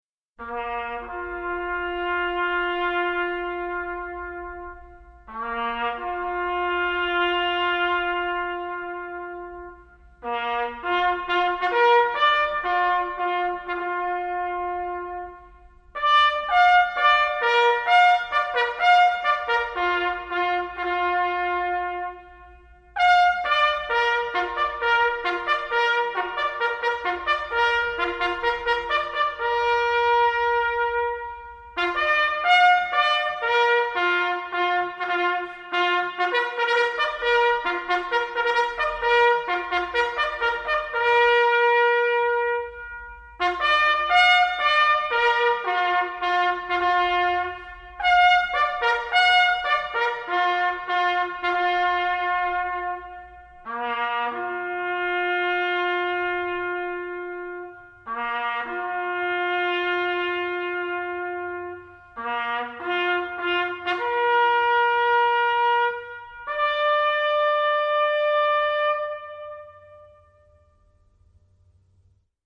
Bugle
The bugle is a melancholy minitrumpet used to evoke a long, bloody day on the battlefield.